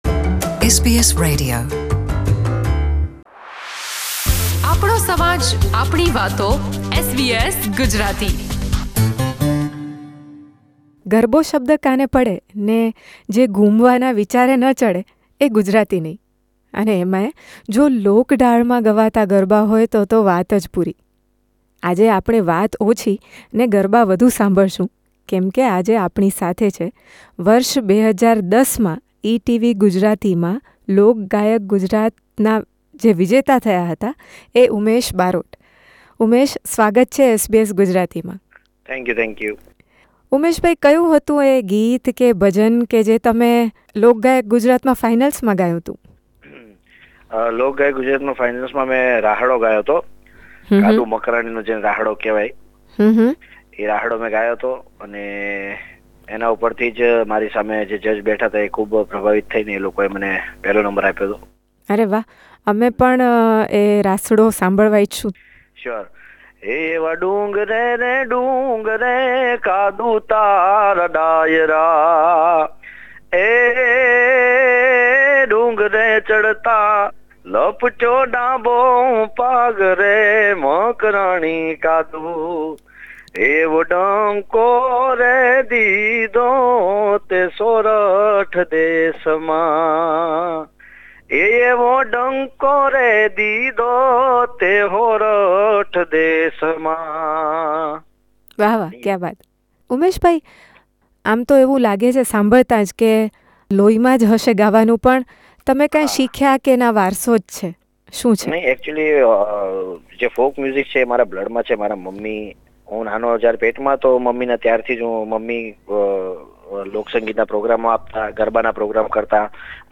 He has complemented this musical gift with classical training. He gives us a taste of Raas, Sugam sangeet and Sufi sangeet during this conversation.